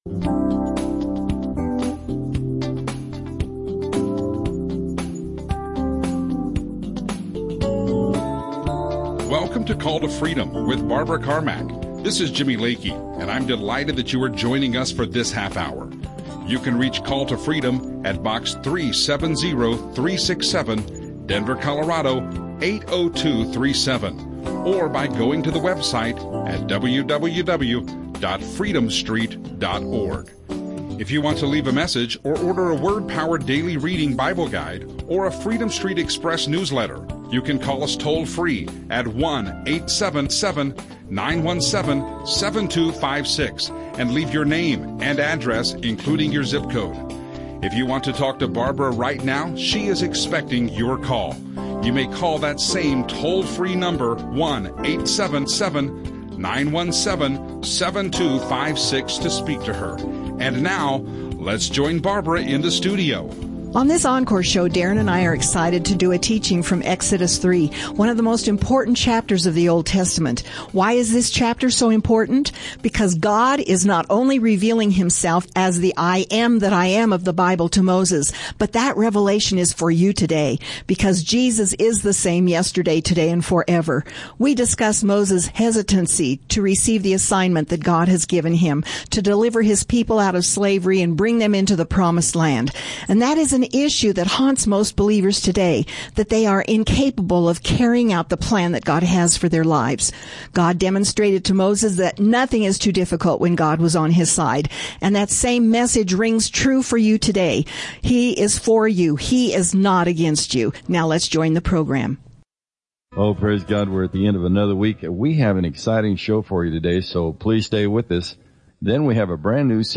Christian radio